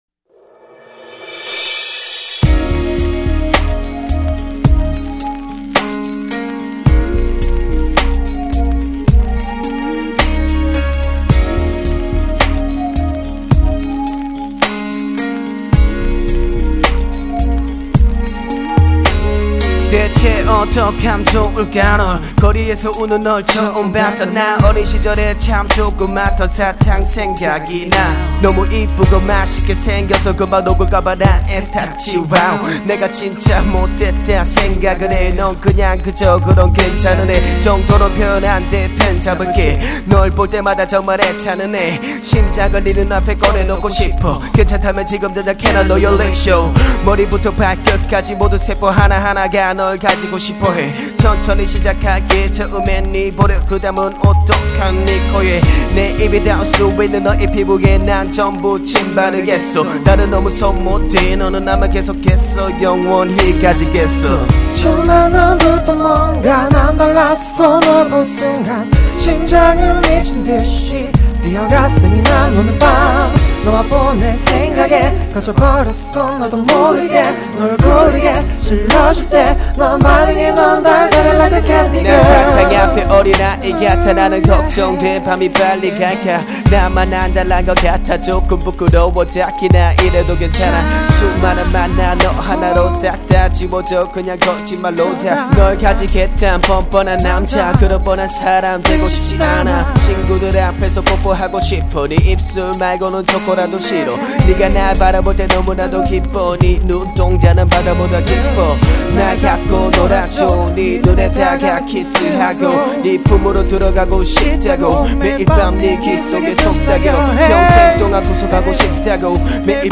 그외에 보컬훅에 사용된 멜로디나 가사, 애드립 등등은 자작 이구요..
감미롭고 좋네요